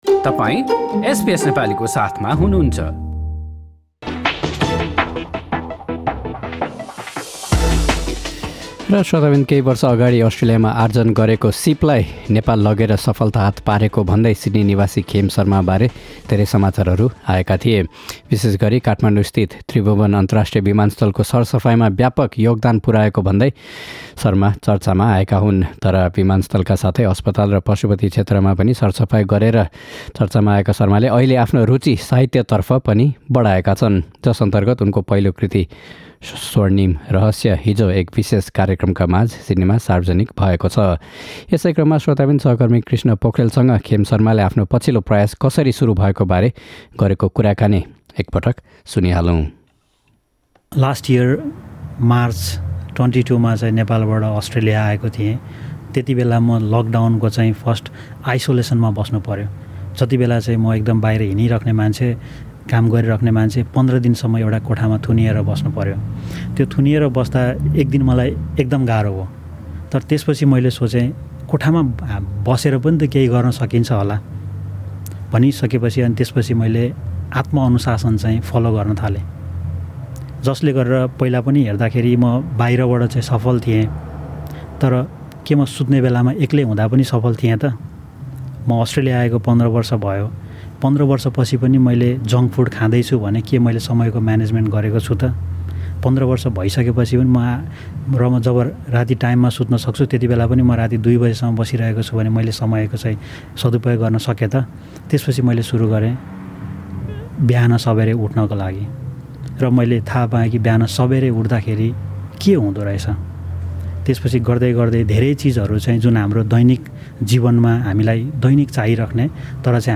हाम्रो कुराकानी सुन्नुहोस्।